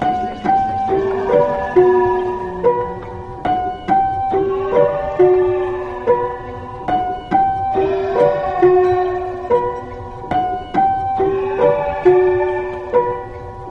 WHO WHAT LOOP (140BPM_Bm).wav